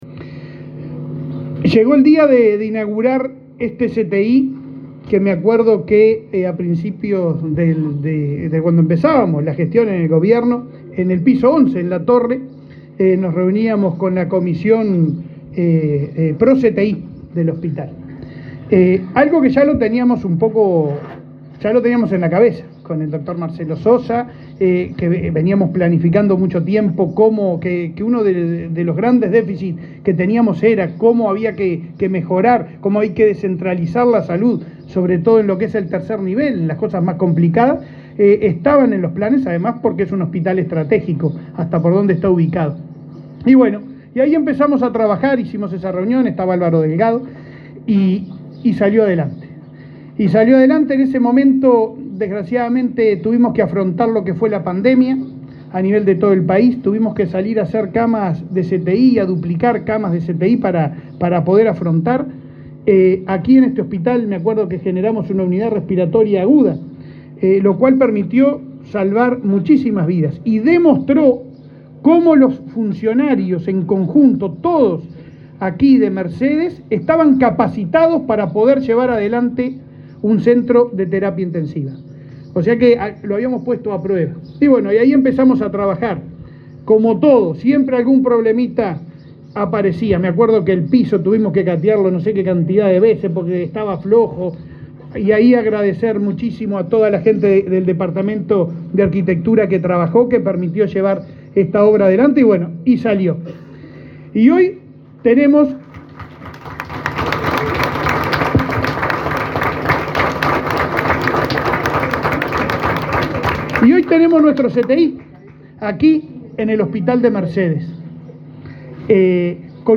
Palabras del presidente de ASSE, Leonardo Cipriani
El presidente de ASSE, Leonardo Cipriani, participó, este viernes 17 en el departamento de Soriano, en el acto de inauguración del CTI del hospital de